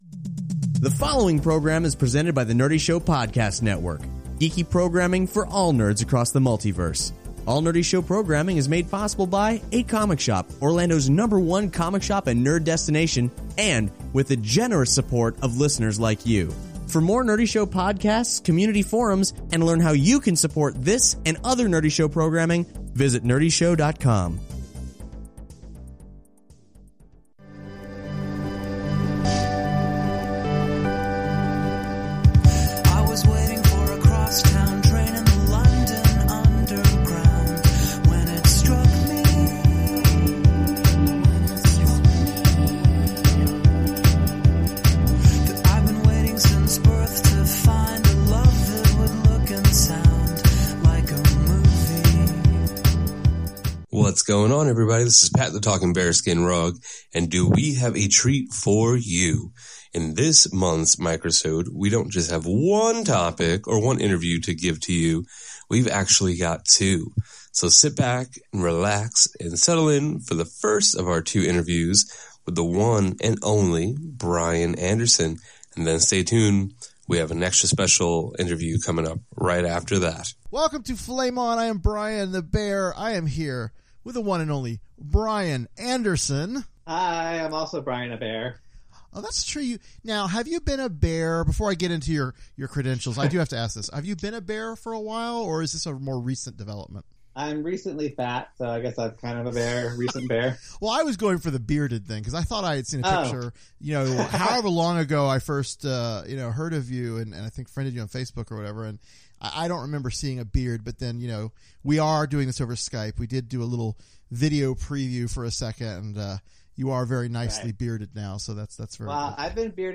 Then we hang out with the mighty Jason Aaron, writer of Thor: God of Thunder, Original Sin, Wolverine & the X-Men, and Southern Bastards – a deliciously bearded bear of a man!